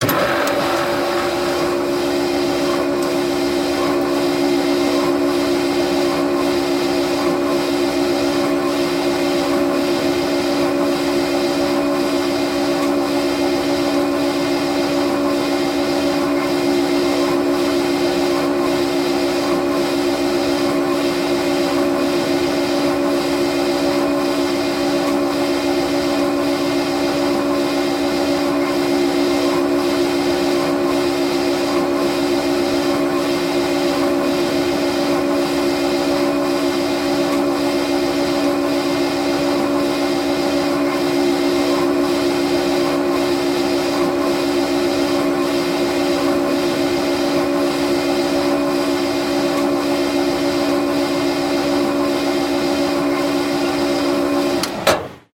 Звуки токарного станка
Шум полировки на токарном станке